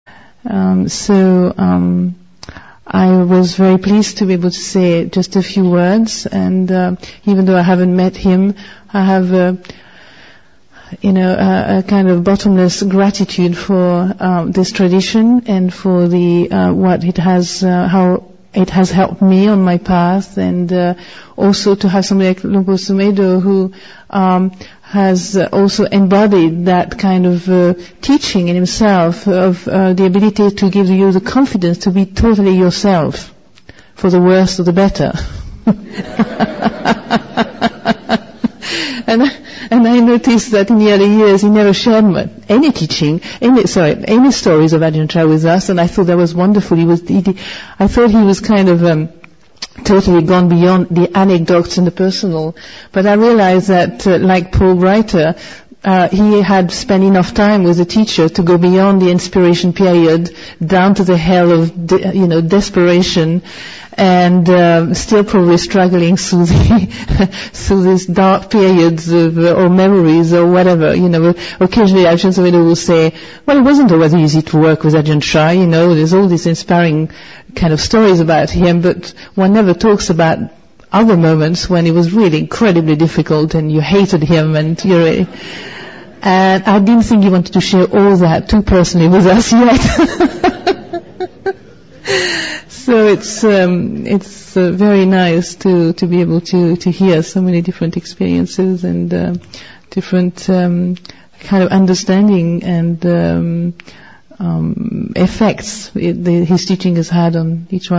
5. The confidence to be totally yourself. Reflection